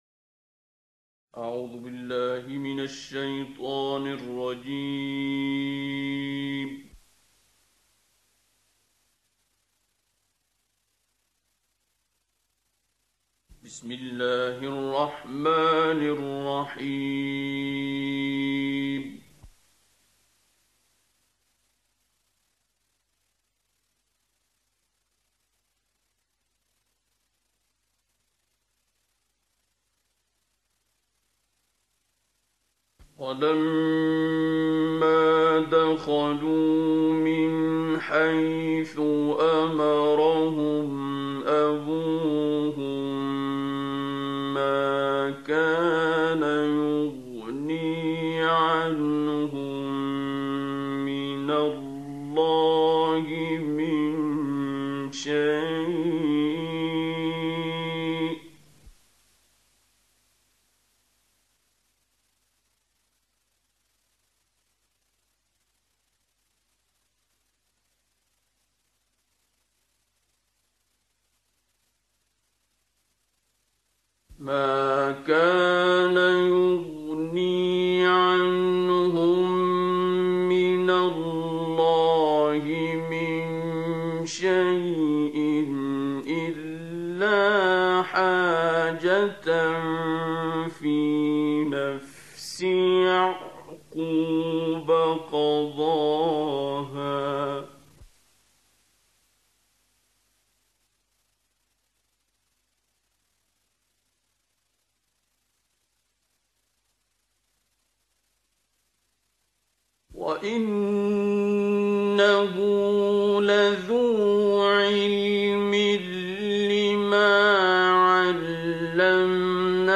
وهالمقطع لعلّه ترضية [ نص المقاطع ترضية ] , رقم المقطع : [ 57 ] صاحب المقطع : [ القارئ / مصطفى إسماعيل ] السورة : [ آل عمران ] المقام : [ عجم ] الرابط : ..
, وفيها لقاء في البداية !